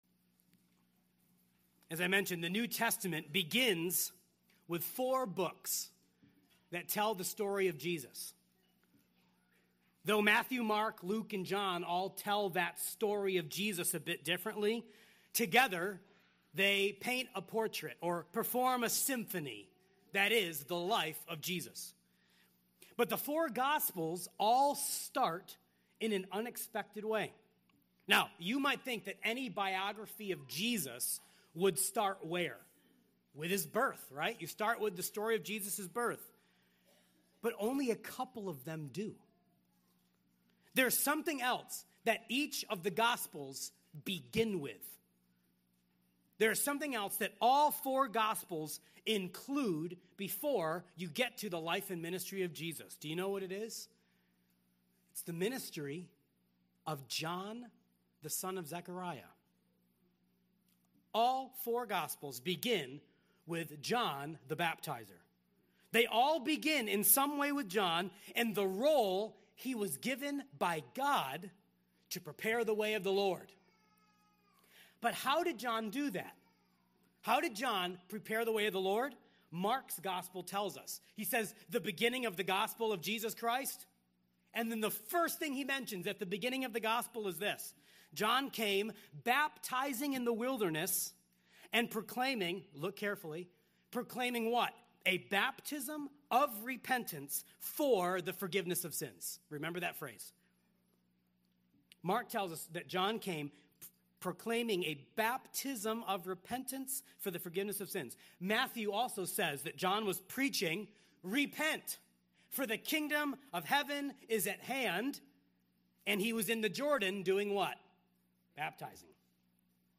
The Baptized Body (Acts 2:37-41) – Emmanuel Community Church